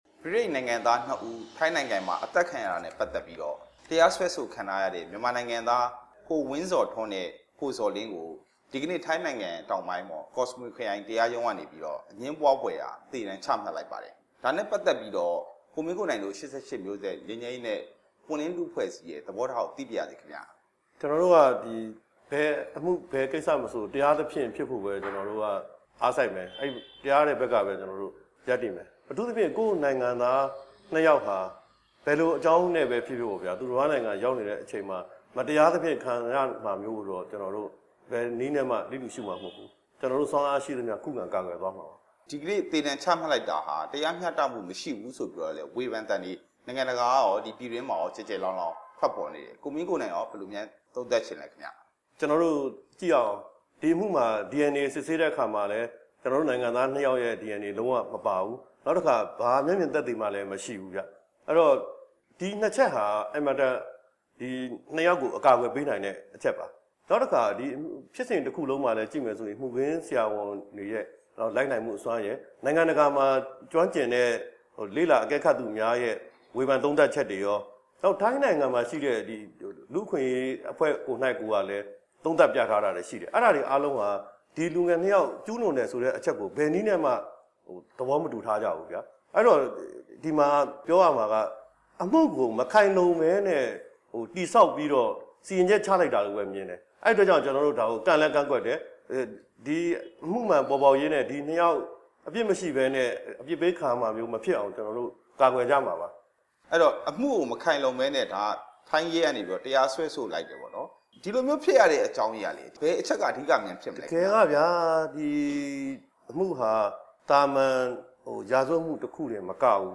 ကိုမင်းကိုနိုင်နဲ့ မေးမြန်းချက် နားထောင်ရန်